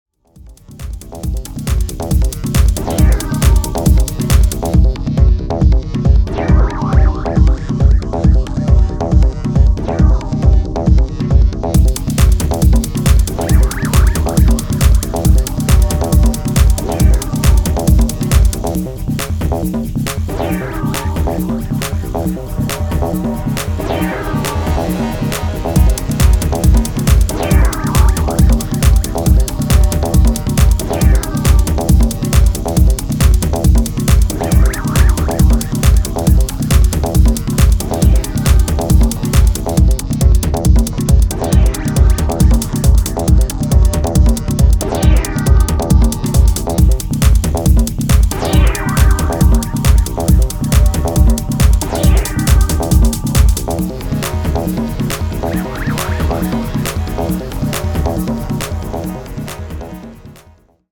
どちらもシンプルでフロア訴求力抜群な仕上がりが推せます！